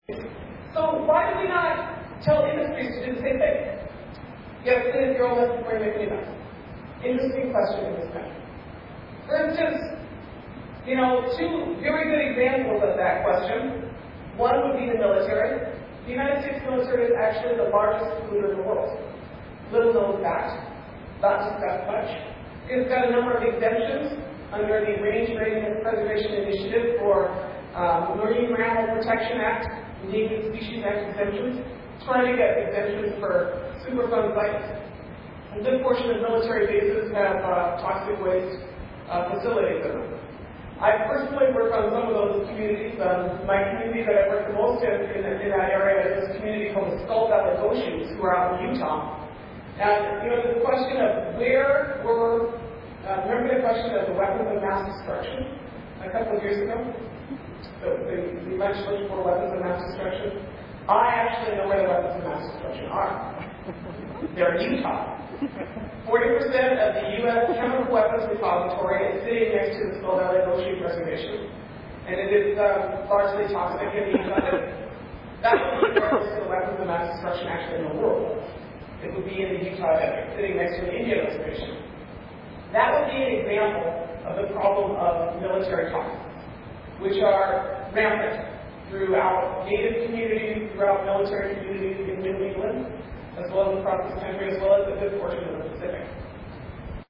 LISTEN TO SELECTIONS OF LADUKE'S LECTURE AT UMASS DARTMOUTH (4/21/05)